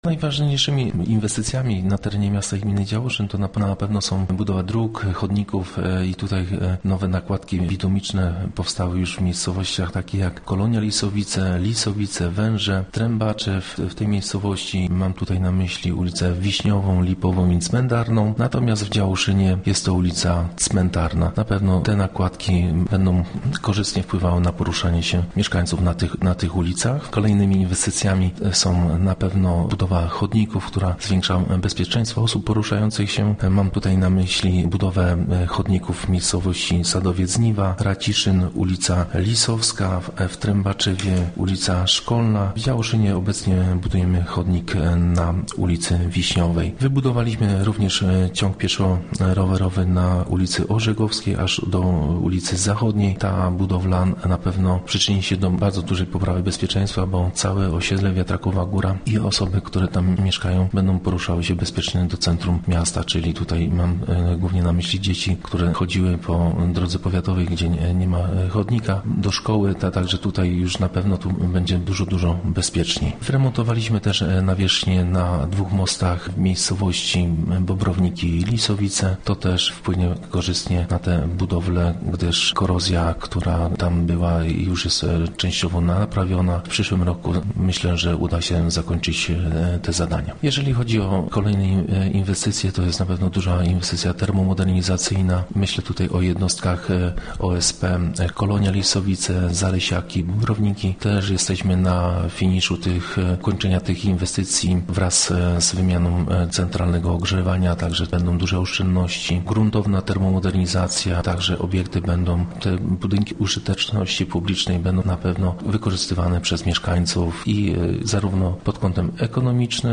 – informował Rafał Drab, burmistrz miasta i gminy Działoszyn